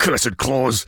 B_claws.ogg